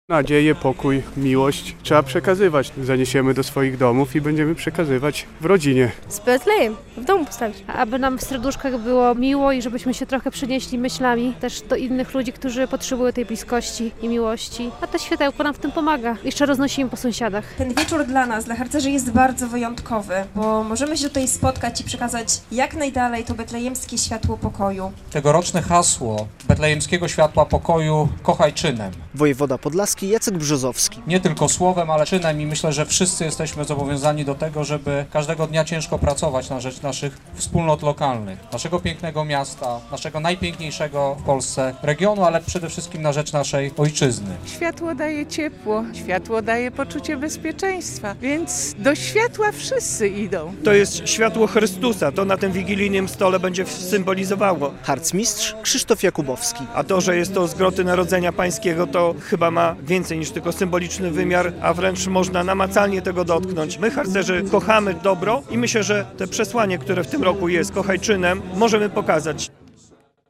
Betlejemskie Światło Pokoju pojawiło się w poniedziałek (23.12) na placu przed Pałacem Branickich w Białymstoku.
Betlejemskie Światło Pokoju pojawiło się przed Pałacem Branickich - relacja